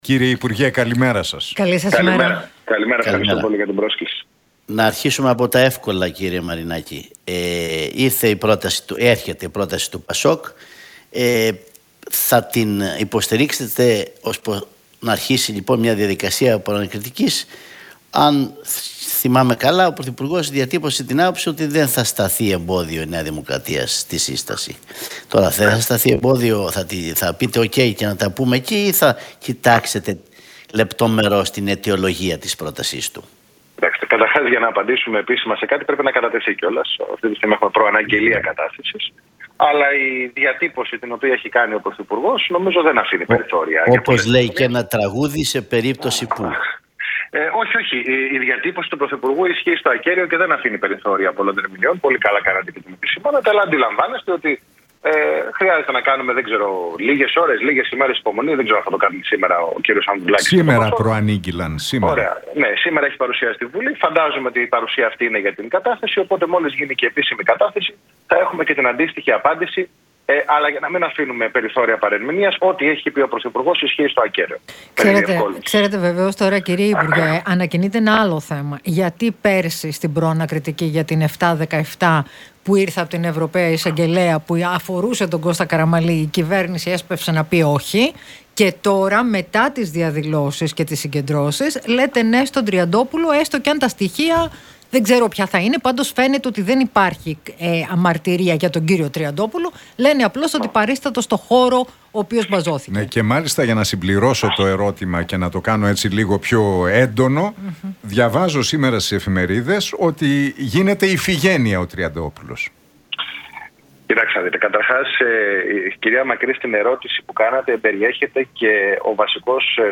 Μαρινάκης στον Realfm 97,8: Πλέον δεν μπορεί η αντιπολίτευση να κρύβεται πίσω από αόριστες κατηγορίες